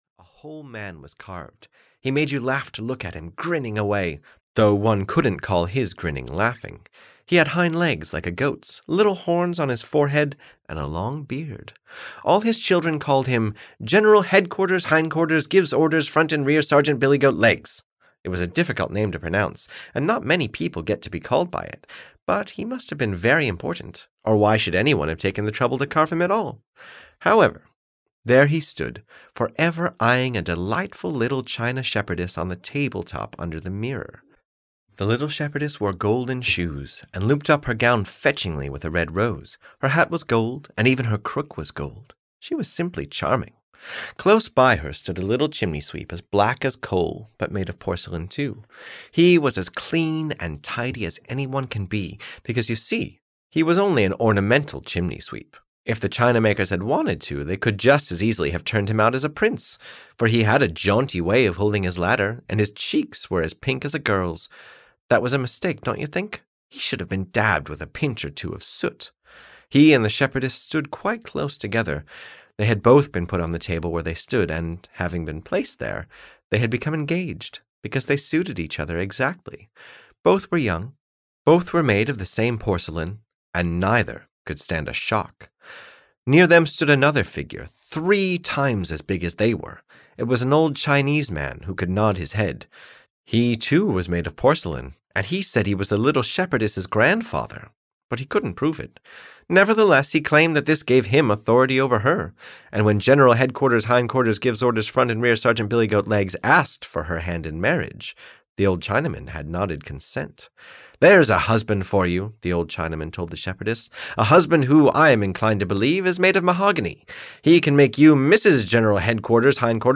The Shepherdess and the Chimney-Sweep (EN) audiokniha
Ukázka z knihy